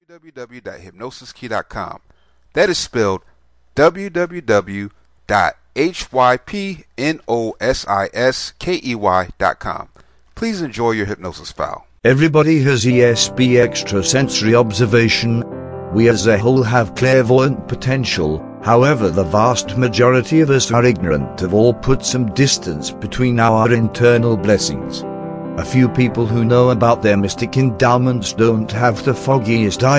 Psychic Ability Visualization Self Hypnosis Mp3
Welcome to Psychic Ability Visualization Self Hypnosis Mp3, this is a powerful self hypnosis.